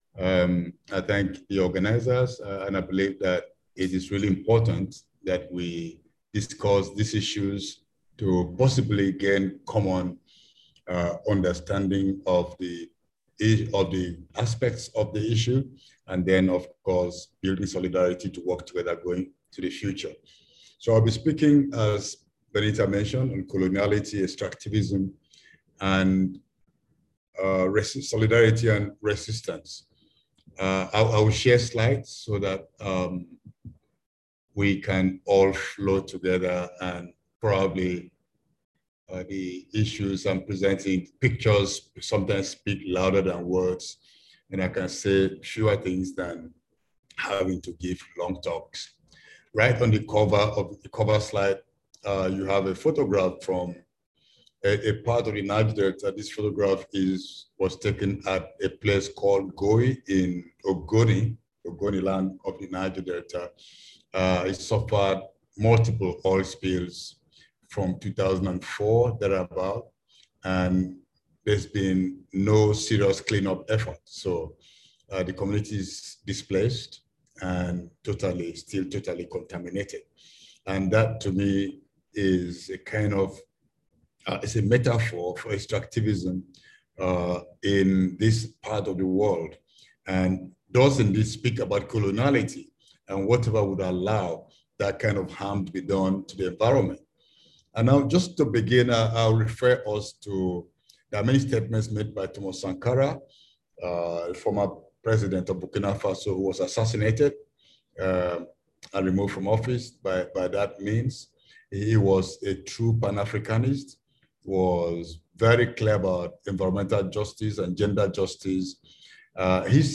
About the presentation (that was followed by participatory discussion): About the Speaker:    Dr Nnimmo Bassey is a Nigerian architect, environmental activist, author and poet.